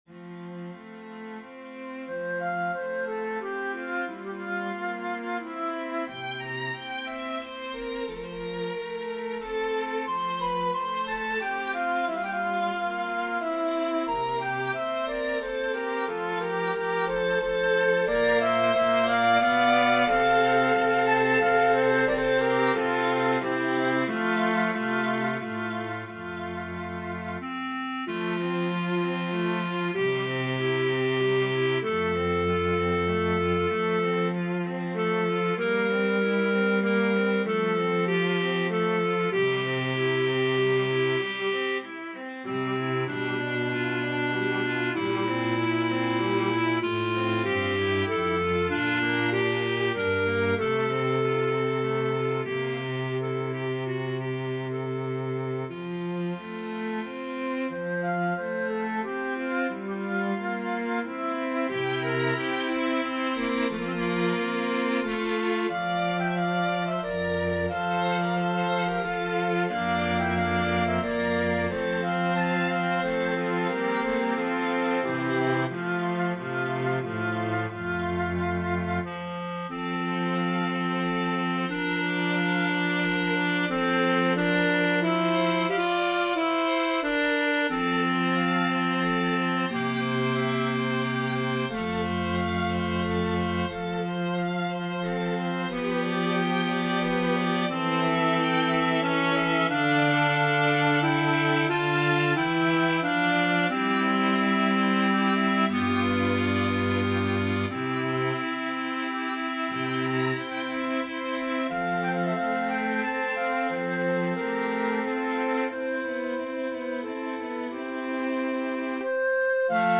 Mixed Woodwind & String Ensembles
Flute,Clarinet,Violin,Viola,Cello